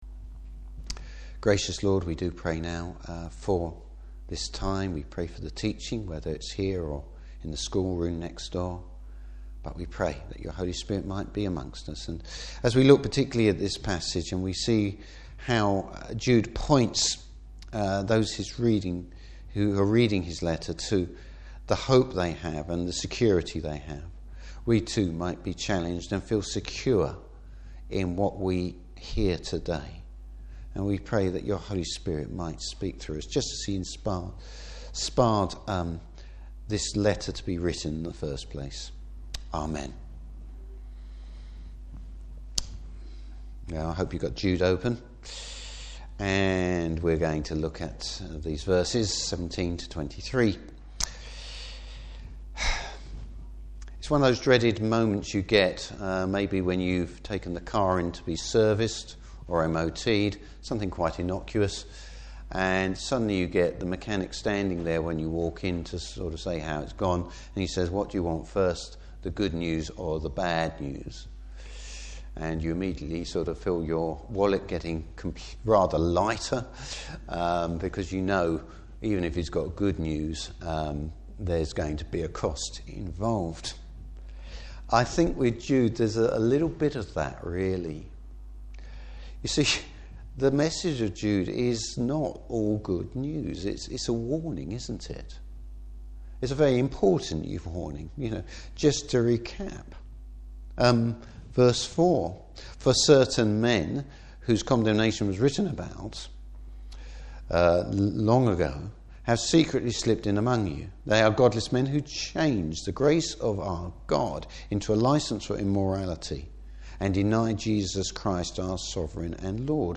Service Type: Morning Service You’ve had the bad news, now for the good news!